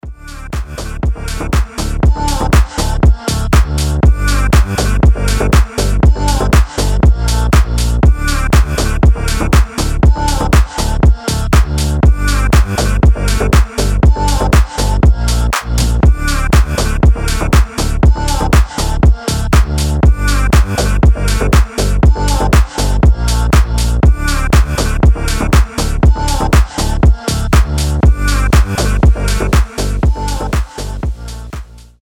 • Качество: 320, Stereo
deep house
атмосферные
без слов
басы
Атмосферный дипчик с басами